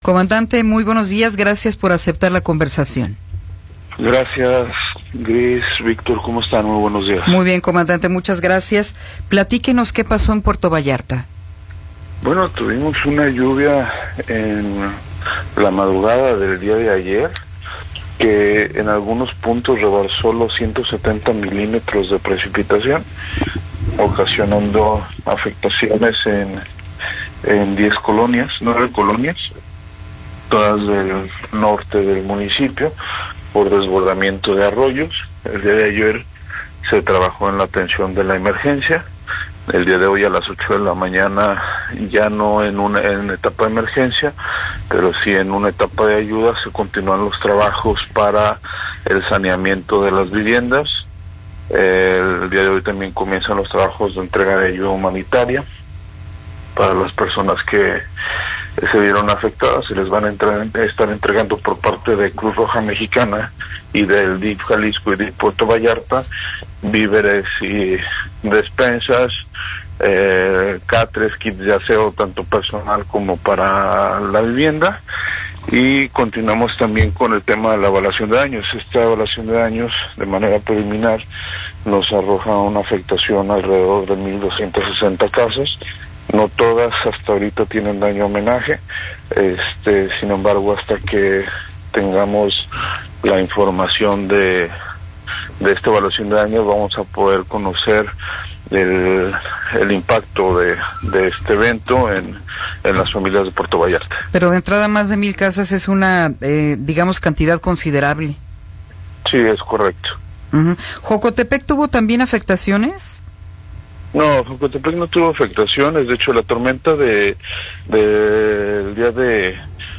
Entrevista con Sergio Ramírez López